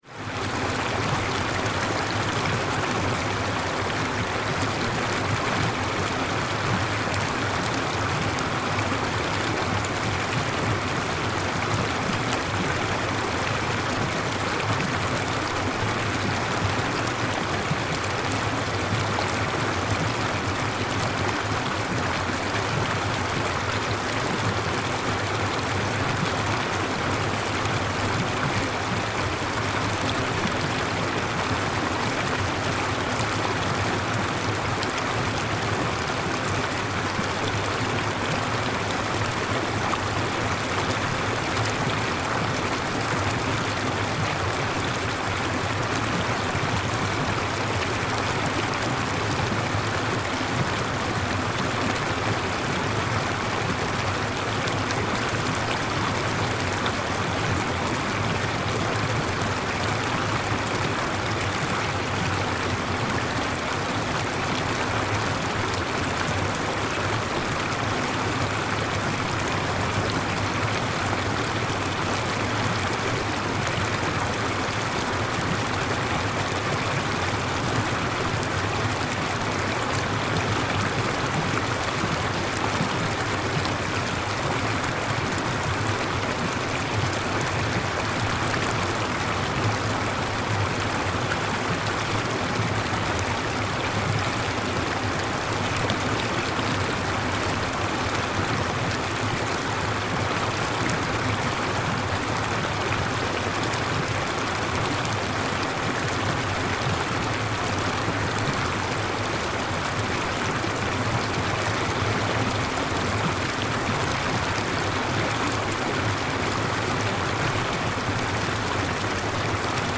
Flowing Water ringtone free download
Sound Effects